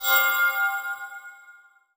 magic_shinny_high_tone_05.wav